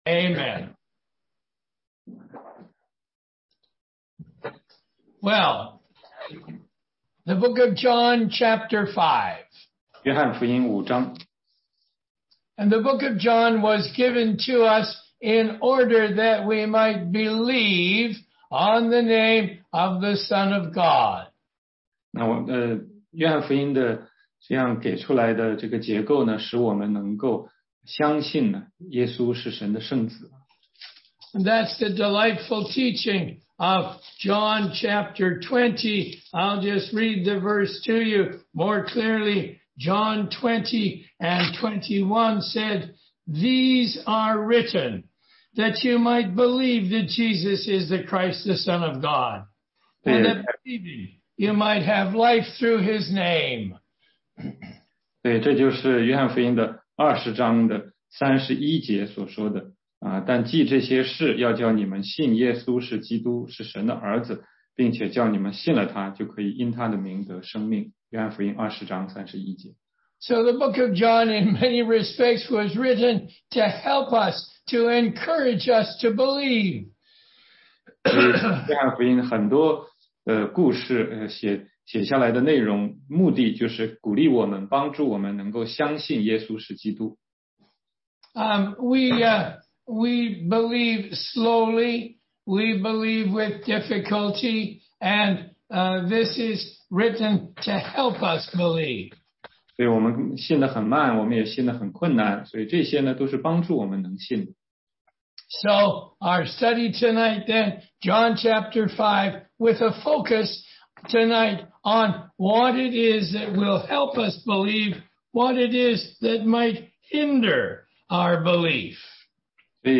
16街讲道录音 - 福音基础